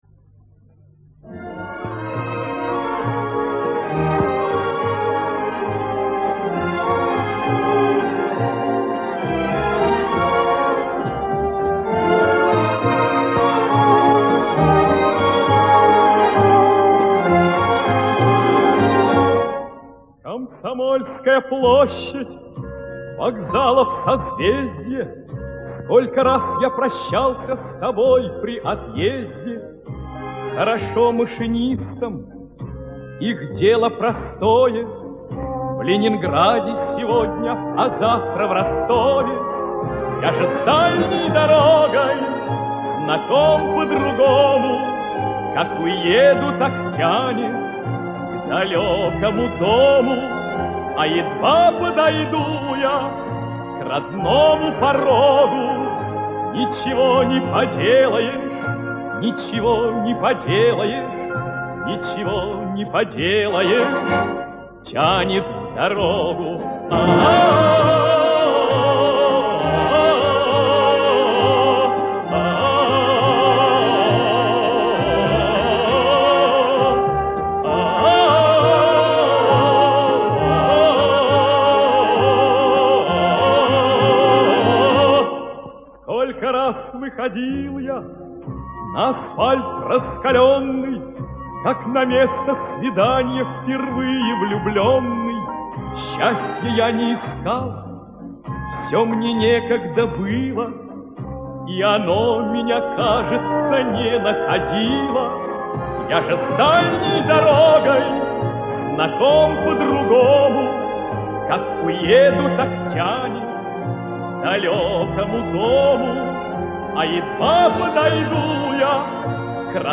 Музыкальное сопровождение: Эдуард Хиль - Комсомольская площадь (муз. Э. Ханка - сл. Е. Доматовского)